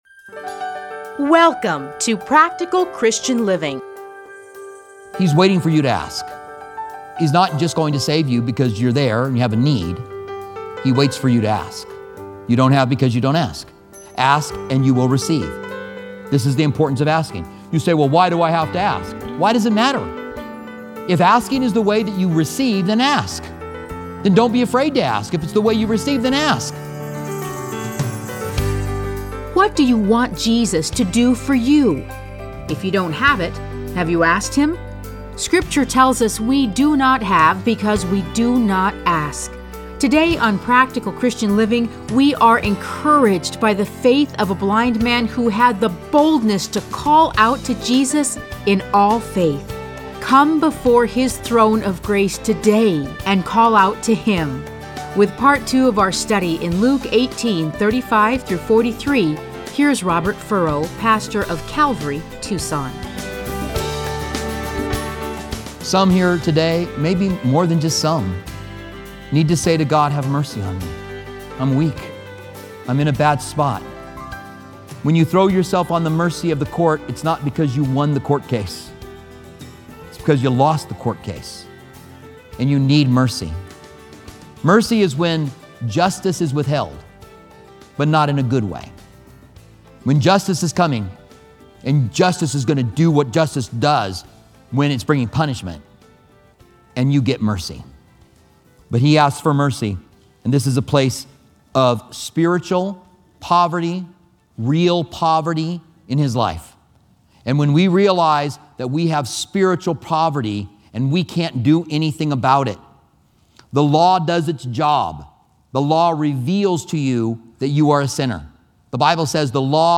Listen to a teaching from Luke 18:35-43.